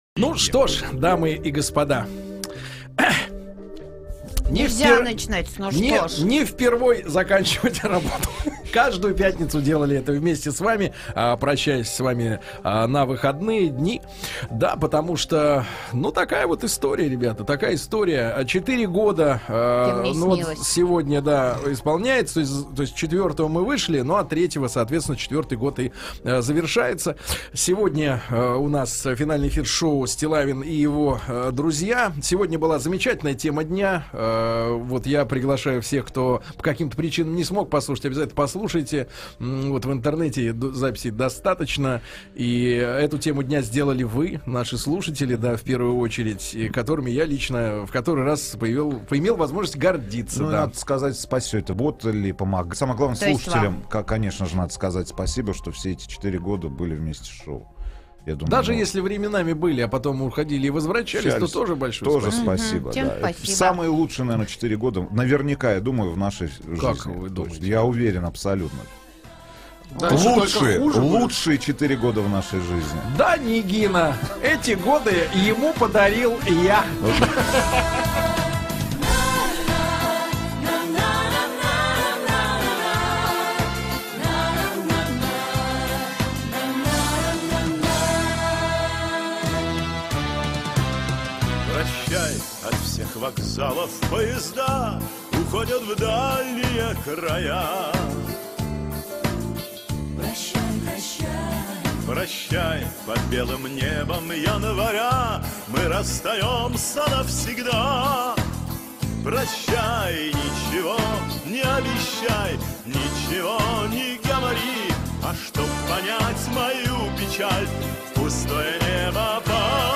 3 февраля 2012 года на радио "Маяк" закрылось шоу "Стиллавин и его друзья". Последние 4 минуты эфира.